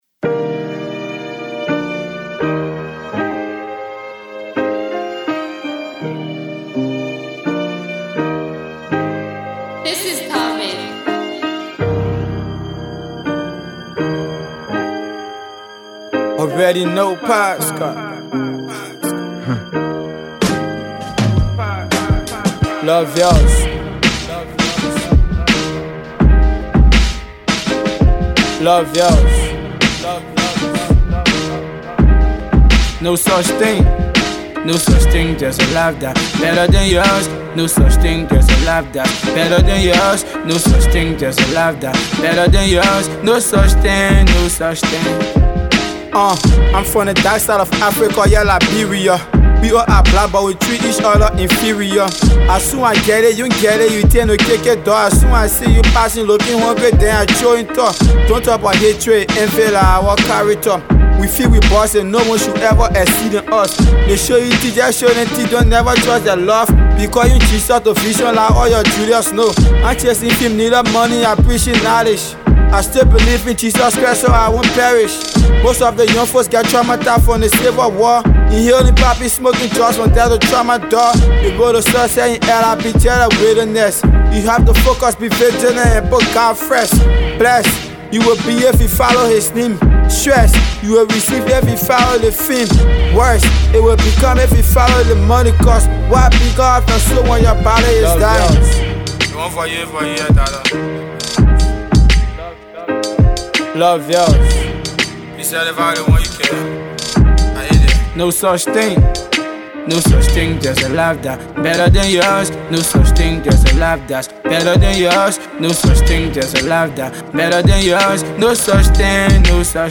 / Hip-Hop, Hip-Co / By